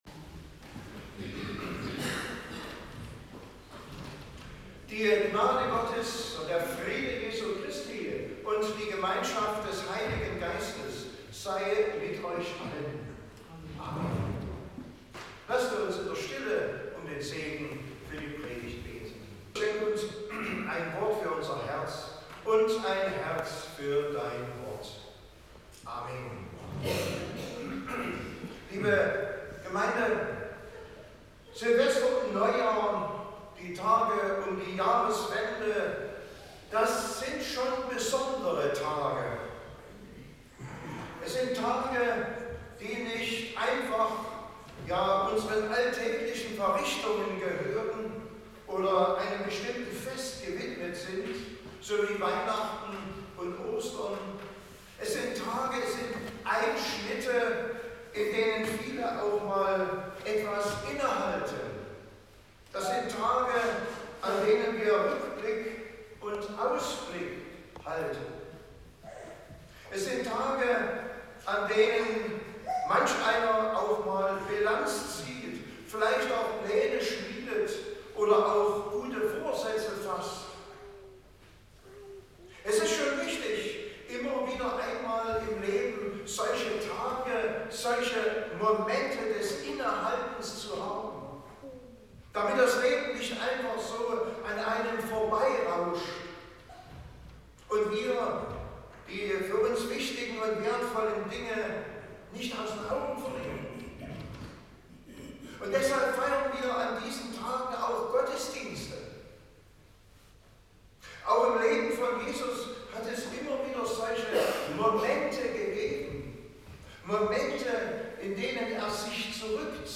Lukas Gottesdienstart: Predigtgottesdienst Stangengrün « Kommt brecht auf